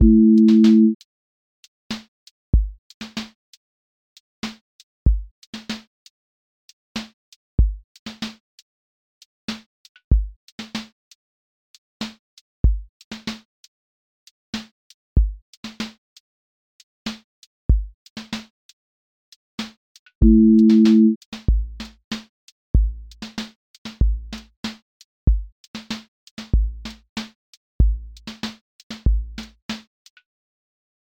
QA Listening Test r&b Template: rnb_pocket
r&b pocket with warm chord bed
• voice_kick_808
• voice_snare_boom_bap
• voice_hat_rimshot
• voice_sub_pulse